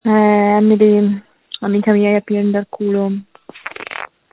JUST SCREAM! Screams from December 1, 2020
• When you call, we record you making sounds. Hopefully screaming.